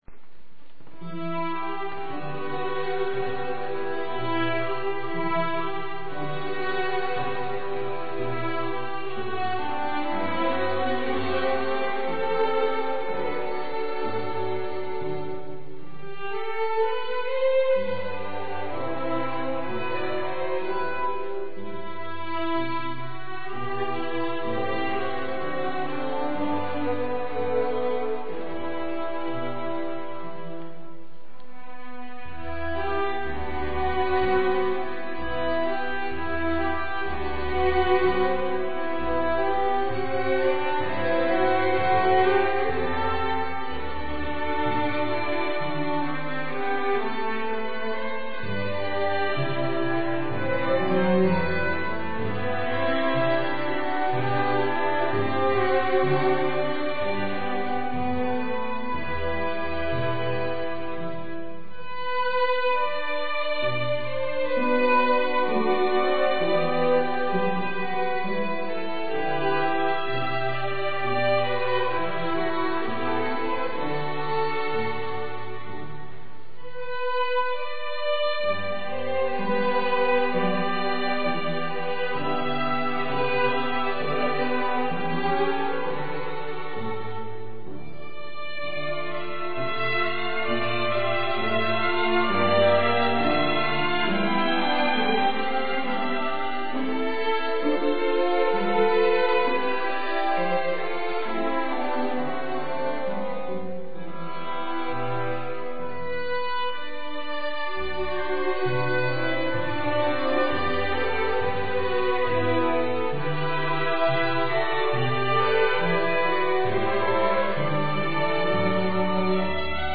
Live-Aufnahmen
ref. Kirche Pfäffikon ZH